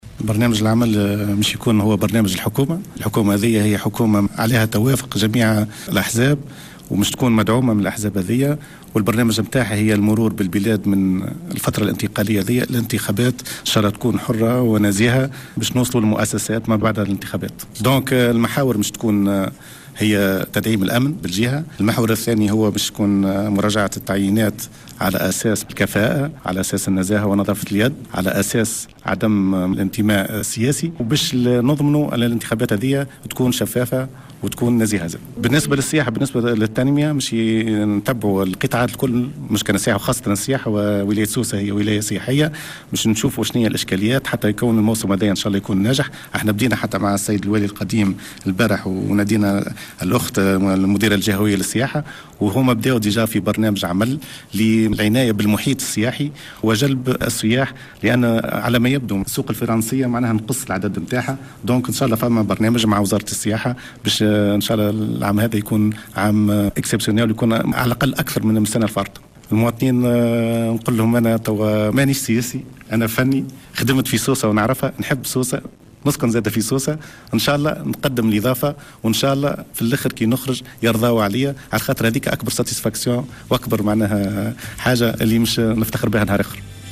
Le nouveau gouverneur de Sousse, Abdelmalek Sallemi, a déclaré dans une intervention sur les ondes de Jawhara FM, ce mardi 4 mars 2014, en marge de sa cérémonie d’investiture, que l’une des plus importantes priorités est de renforcer et assurer la sécurité dans la région, et examiner, par la suite, les recrutements dans la fonction publique, se basant sur la compétence, l’intégrité et l’indépendance politique.